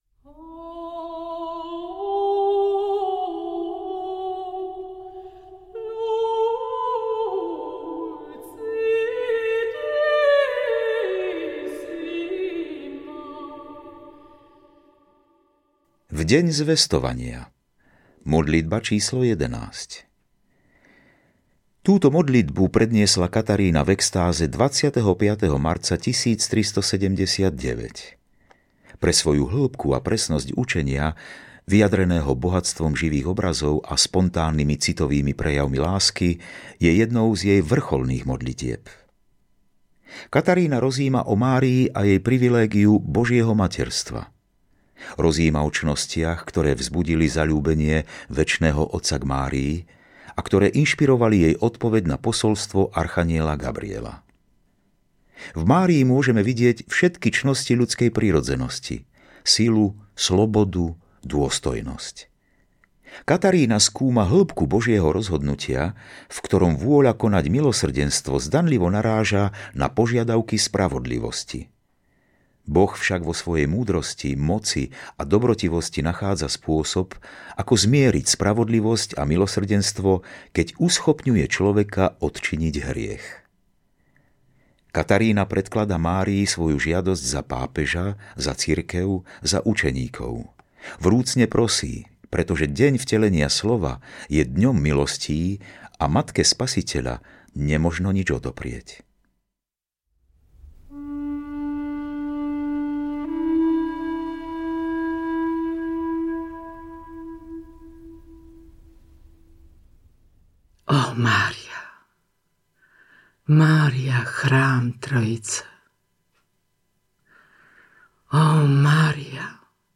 Audiokniha Svätá Katarína Sienská – Modlitby, ktorú napísal Giuliana Cavallini prináša hlboké duchovné slovo jednej z najvýznamnejších mystičiek Cirkvi. V interpretácii Anny Javorkovej ožívajú modlitby plné viery, krásy a lásky k Bohu.
Ukázka z knihy
• InterpretAnna Javorková, Ján Gallovič